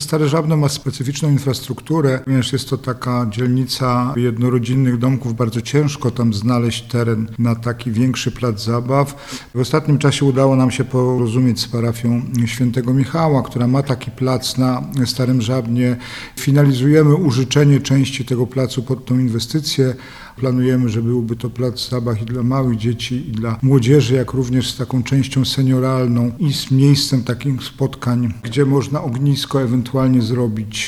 – Dużo czasu poświęciliśmy na znalezienie odpowiedniej działki na realizację zadania – powiedział wiceprezydent Jacek Milewski: